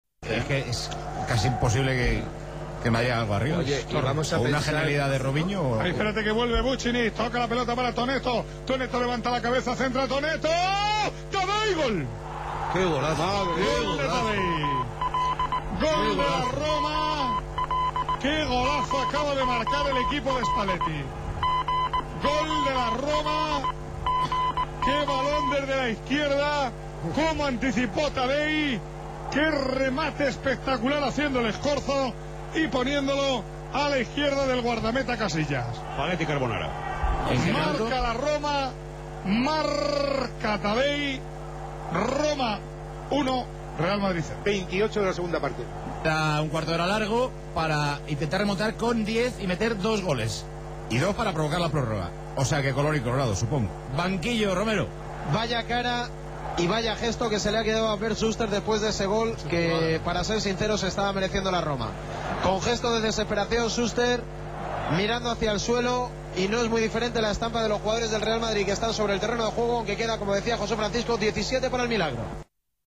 Il commento della radio spagnola ai gol